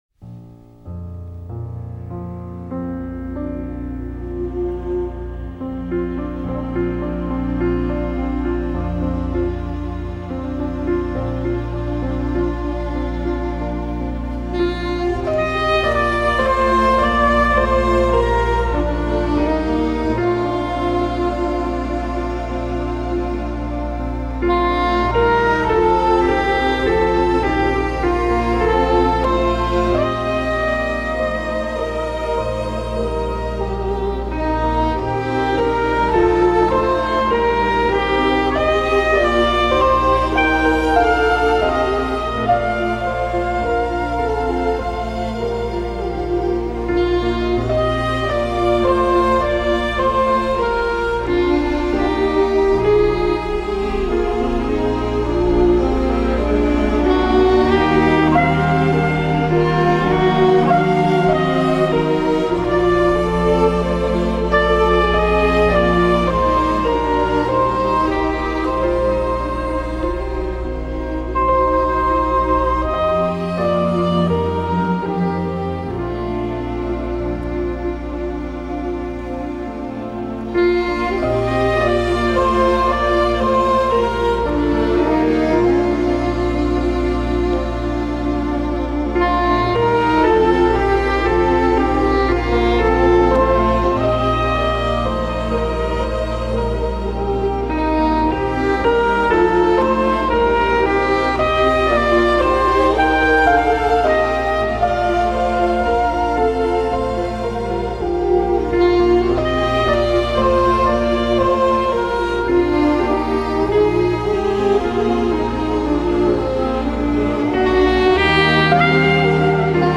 This piece of music is from a film.